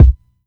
• Subby Bass Drum One Shot C Key 651.wav
Royality free kickdrum sample tuned to the C note. Loudest frequency: 106Hz
subby-bass-drum-one-shot-c-key-651-mmv.wav